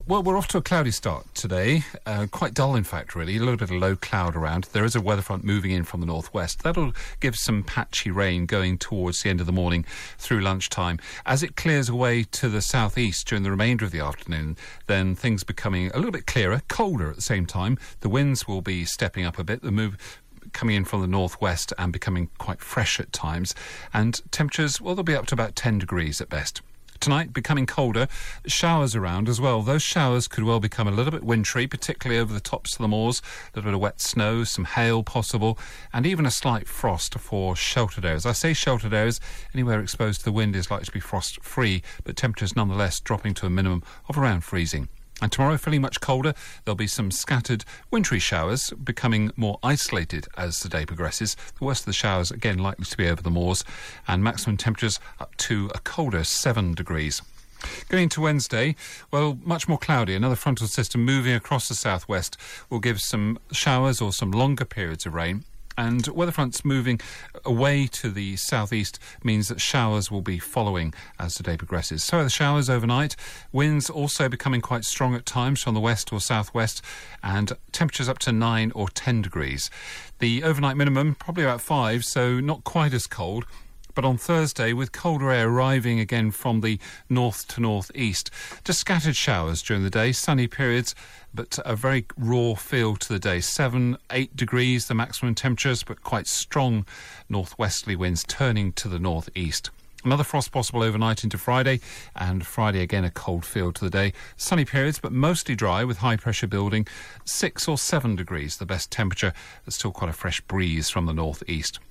5 day forecast for Devon from 8.35AM on 18 November